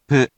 We have our computer friend, QUIZBO™, here to read each of the hiragana aloud to you.
#4.) Which hiragana do you hear? Hint: 【pu】
In romaji, 「ぷ」 is transliterated as 「pu」which sounds sort of like Winnie the「Pooh」, (and it sort of looks like him, too)